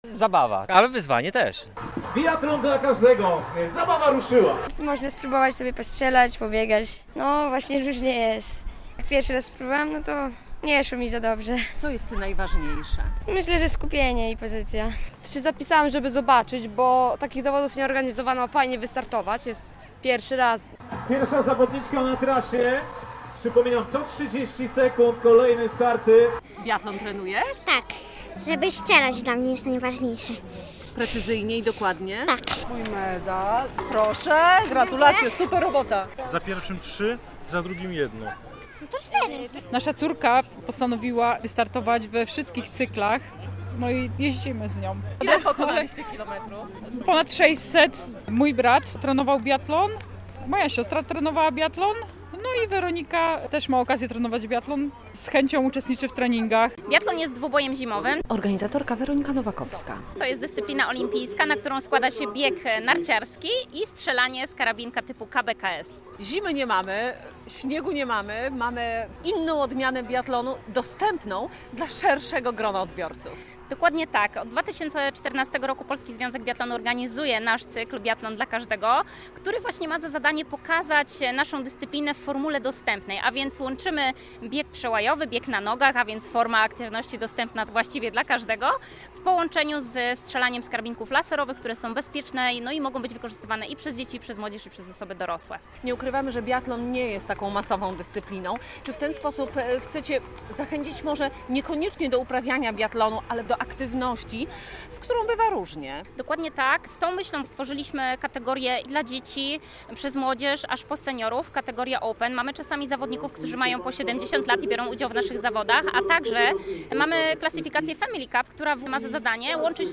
Tak mówili uczestnicy wydarzenia "Biathlon dla każdego". To ogólnopolska inicjatywa, która w sobotę (2.09) po raz pierwszy odbyła się w Białymstoku.
Pierwsze zawody biathlonowe w Białymstoku - relacja